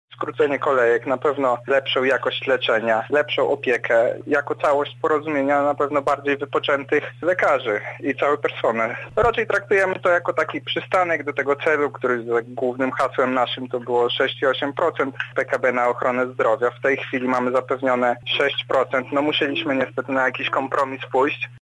lekarz rezydent, wyjaśnia, co w praktyce oznaczają te zmiany: